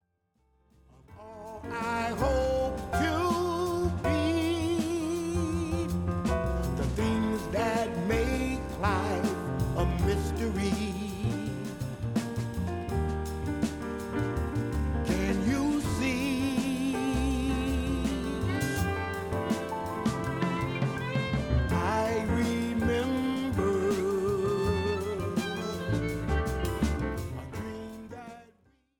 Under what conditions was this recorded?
Recorded principally at Van Gelder Studios